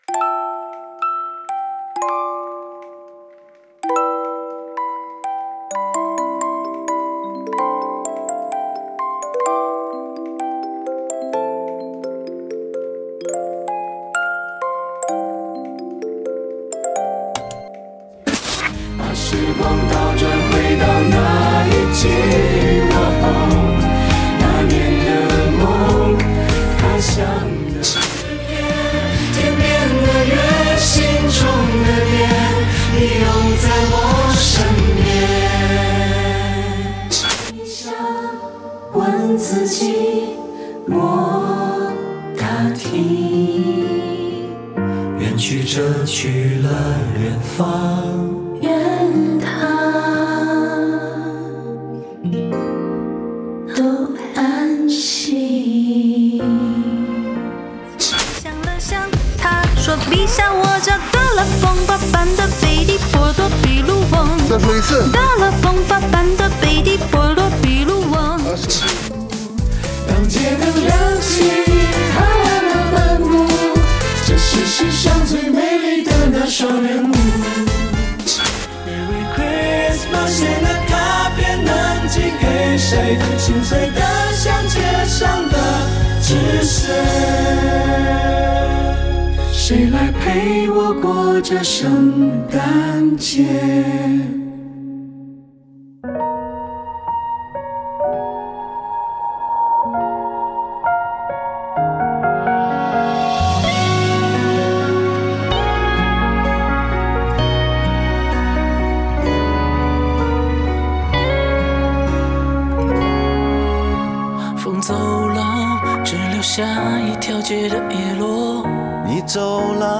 大家不要因爲設備不好或沒唱好而感到沮喪。